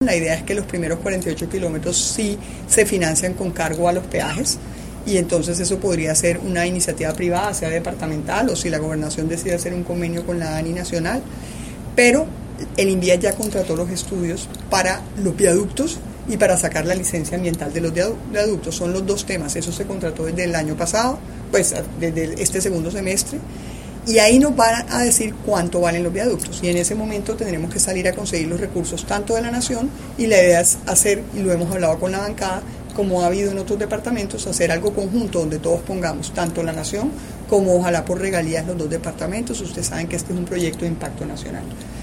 En breves declaraciones en su visita a Barranquilla, la ministra de transporte Ángela María Orozco lamentó la muerte de los dos obreros y las graves lesiones que sufrieron otros tres después del desplome del puente en construcción de la variante sur de Ciénaga – Magdalena el pasado sábado.